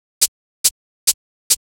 HIHAT030_TEKNO_140_X_SC2.wav